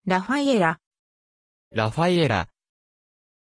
Pronunția numelui Raphaëlla
pronunciation-raphaëlla-ja.mp3